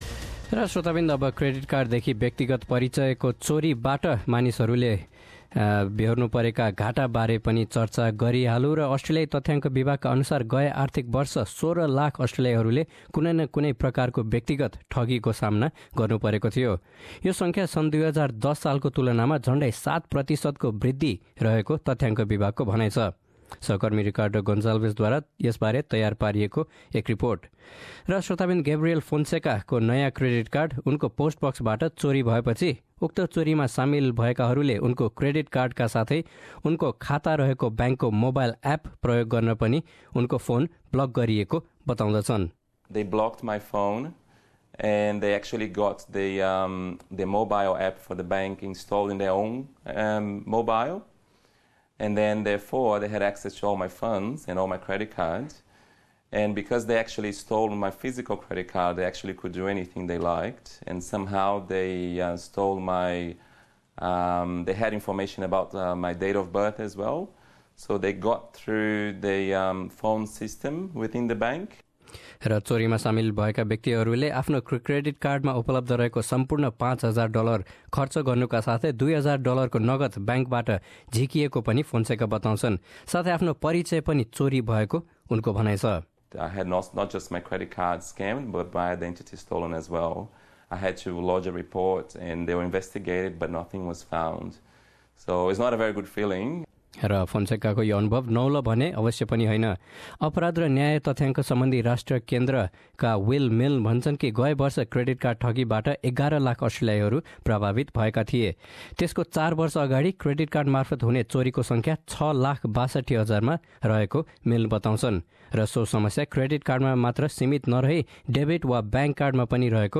गए आर्थिक वर्ष १६ लाख अस्ट्रेलियाईहरुले कुनै न कुनै प्रकारको व्यक्तिगत ठगीको सामना गर्नुपरेको अस्ट्रेलियाई तथ्यांक विभागको भनाइ छ। आफुलाई क्रेडिट र एफ्टपोस कार्ड मार्फत ठगी हुनबाट जोगाउन के गर्न सकिन्छ त? थप रिपोर्ट यहाँ सुन्नुहोस्।